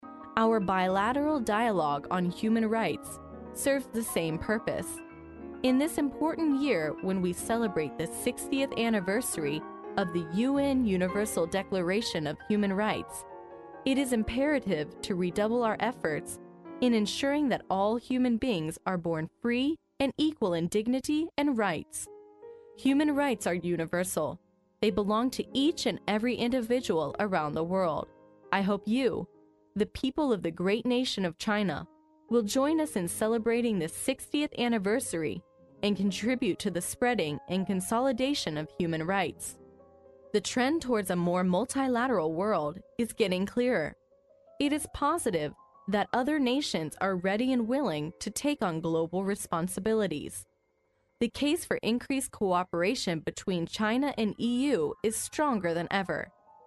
历史英雄名人演讲 第46期:欧盟委员会主席巴罗佐在国家行政学院的演讲(2) 听力文件下载—在线英语听力室